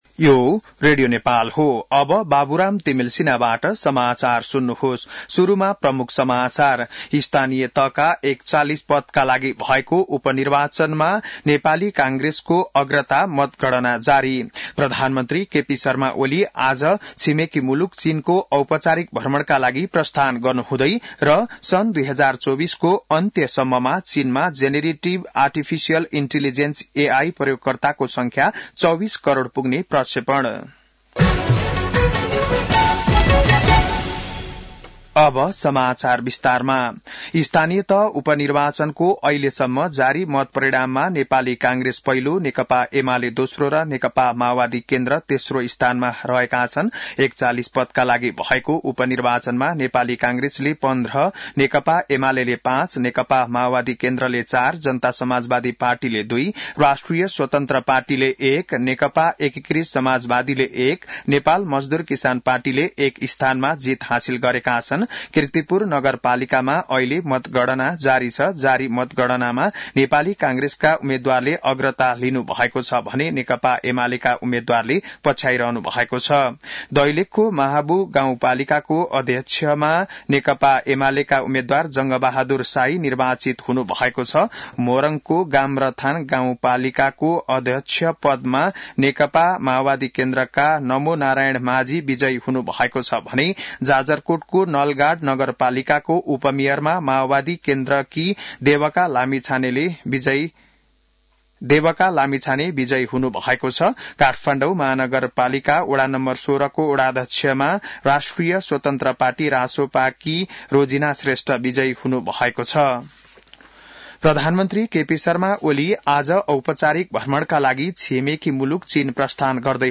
बिहान ९ बजेको नेपाली समाचार : १८ मंसिर , २०८१